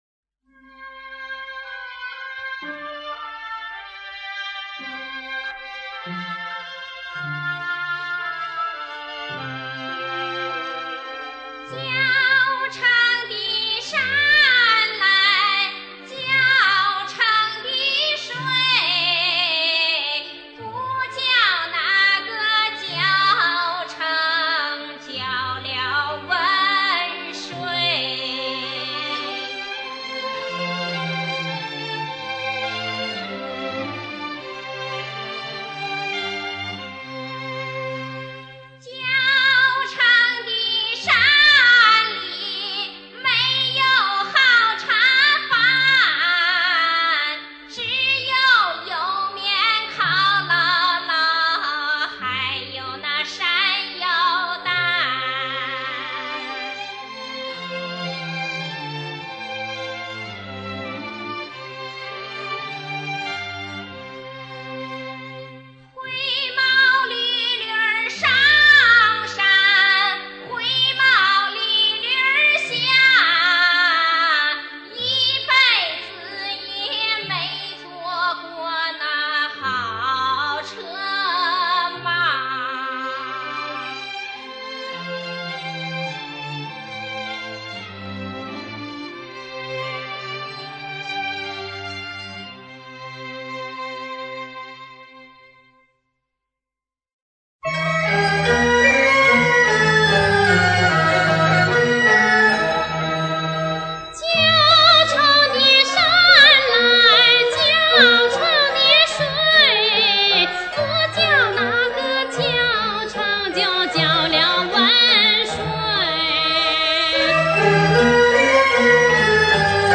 山西民歌